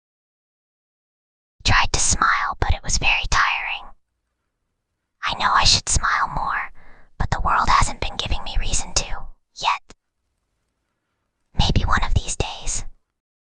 Whispering_Girl_17.mp3